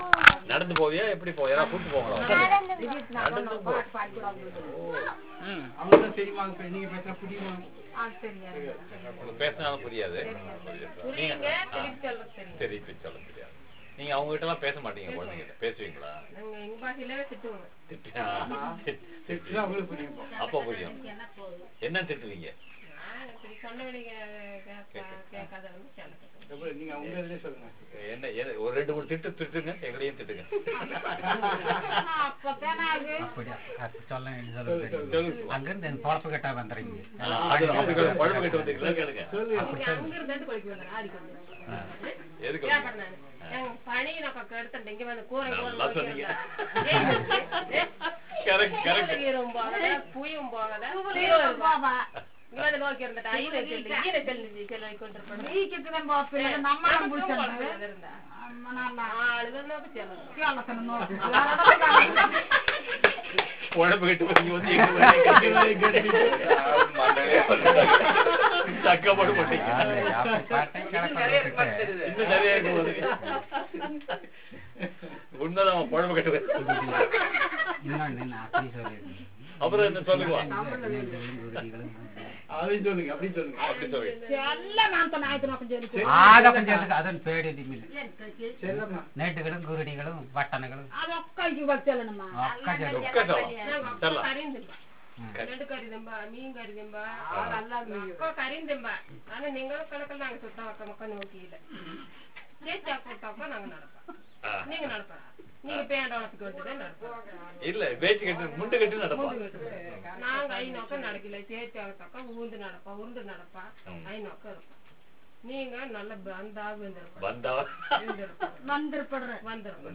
Performance of songs in Eravallan